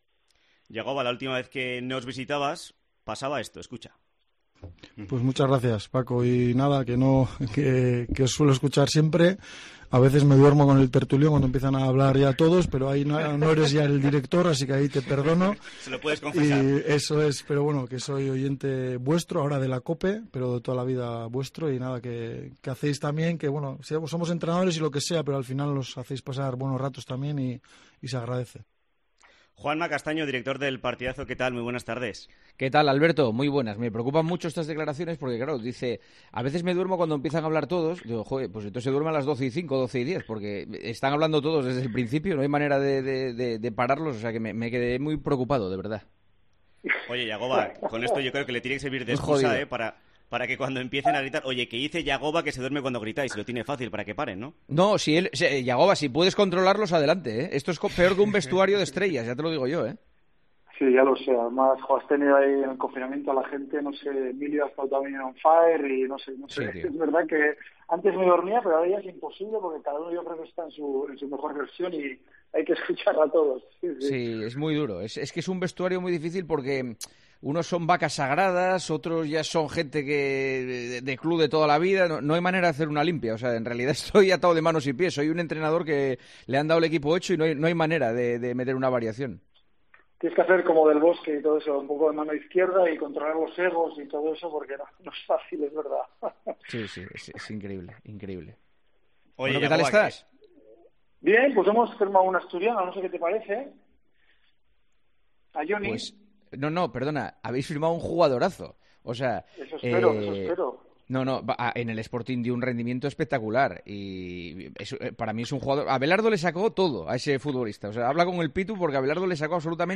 El entrenador rojillo ha hablado en COPE Navarra con el director de El Partidazo de COPE
Juanma Castaño charla con Jagoba Arrasate en COPE Navarra